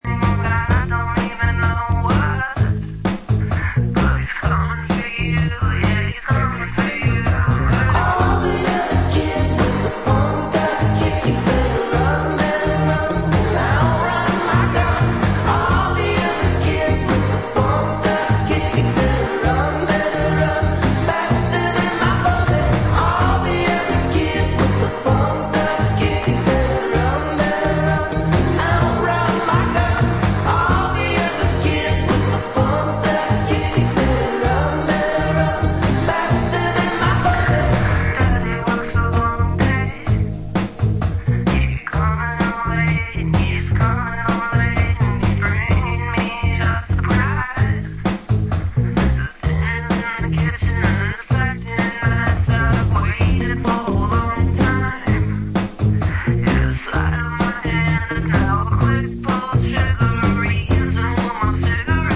feel good indie-pop album with some great singles.